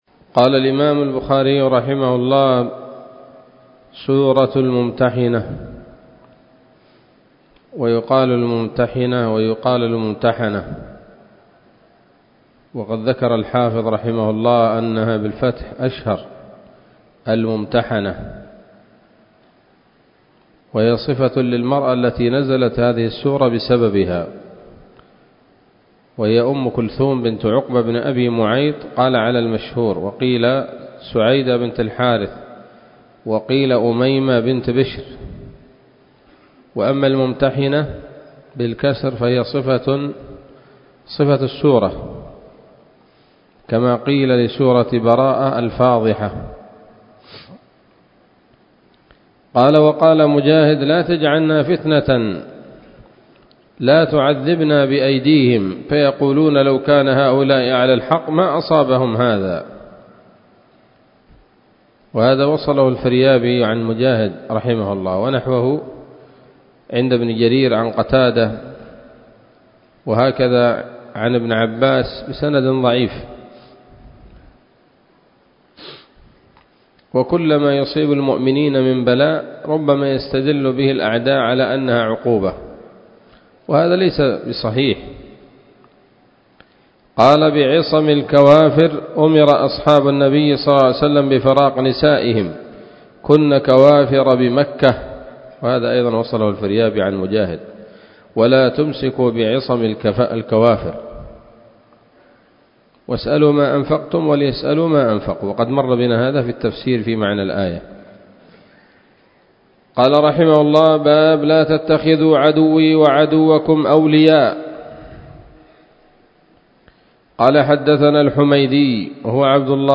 الدرس السادس والخمسون بعد المائتين من كتاب التفسير من صحيح الإمام البخاري